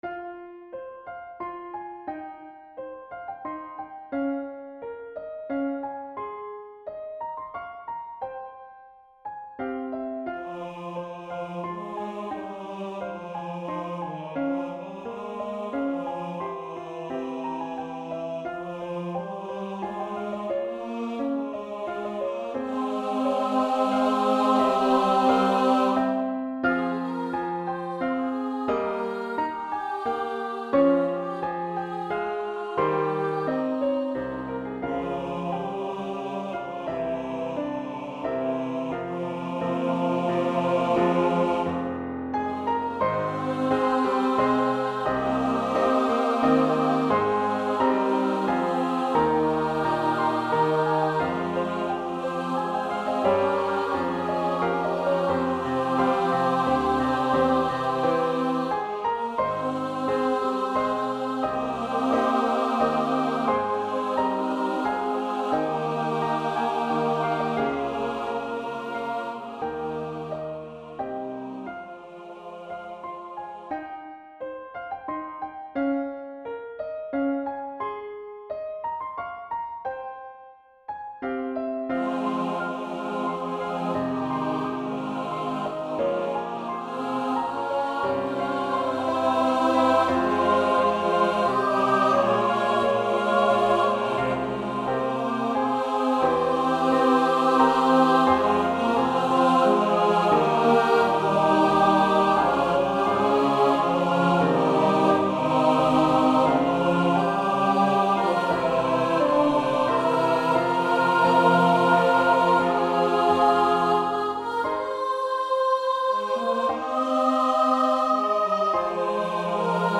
SATB and Piano
Anthem
Church Choir